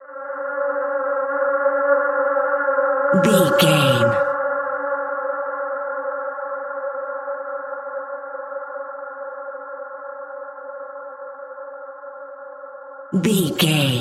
Sound Effects
Atonal
Slow
ominous
dark
haunting
eerie
synthesiser
ambience
pads
eletronic